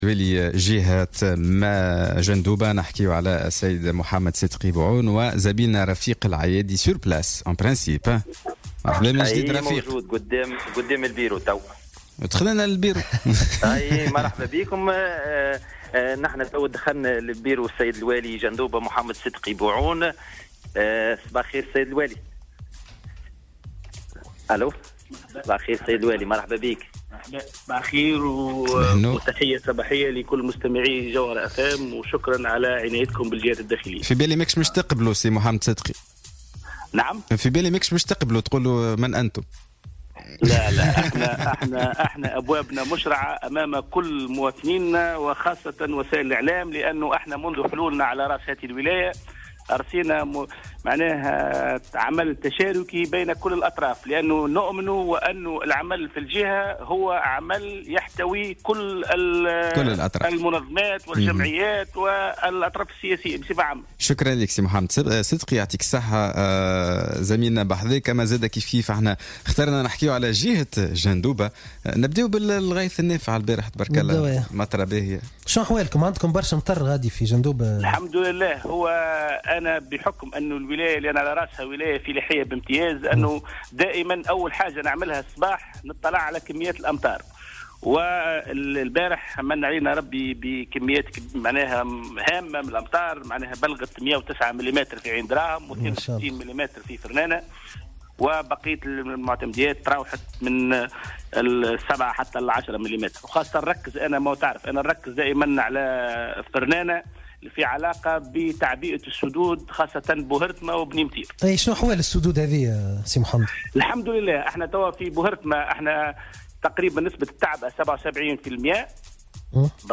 زار والي الجهة في مكتبه للحديث في ربط مباشر